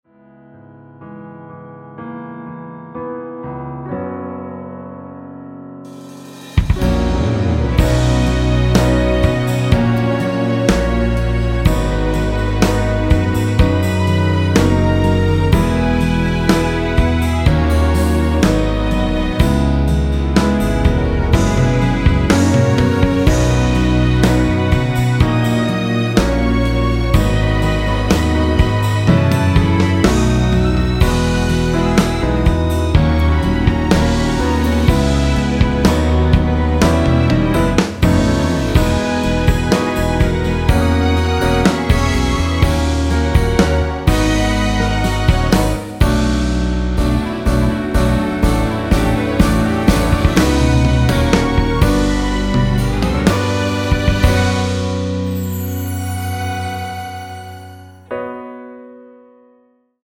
원키에서(-2)내린 1절후 후렴으로 진행 되는 MR입니다.(본문 가사 확인)
◈ 곡명 옆 (-1)은 반음 내림, (+1)은 반음 올림 입니다.
앞부분30초, 뒷부분30초씩 편집해서 올려 드리고 있습니다.
중간에 음이 끈어지고 다시 나오는 이유는